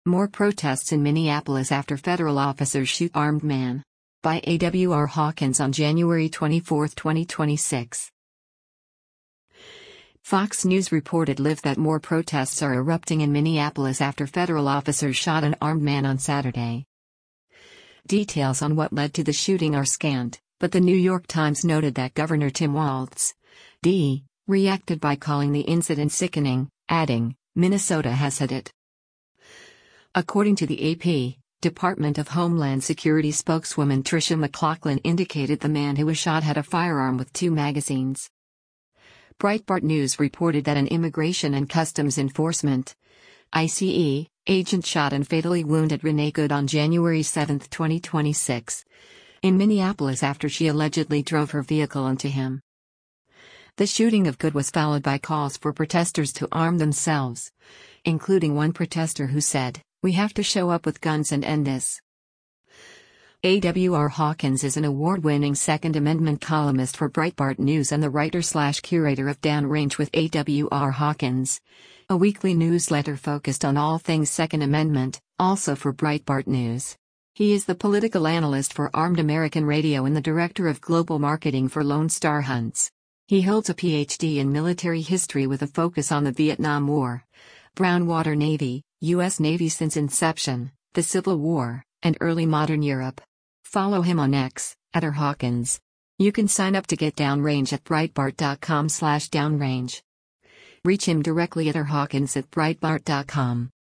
FOX News reported live that more protests are erupting in Minneapolis after federal officers shot an armed man on Saturday.